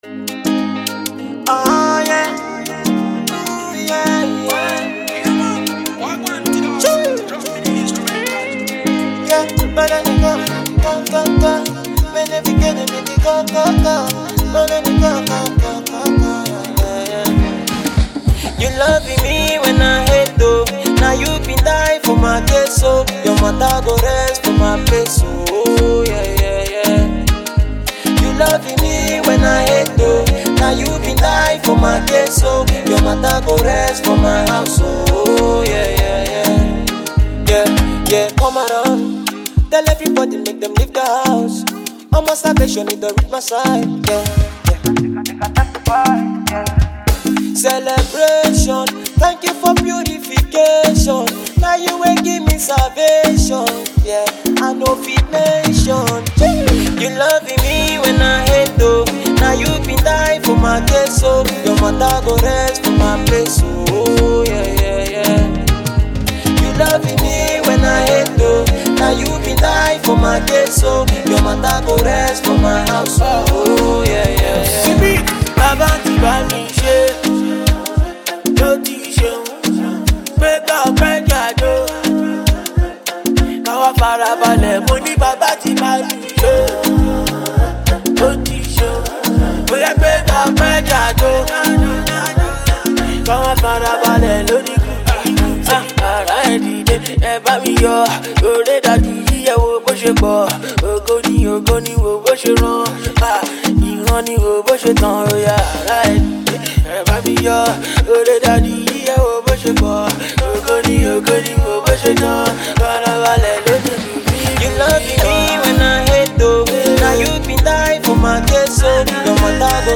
Afro-fusion rapper
indigenous rapper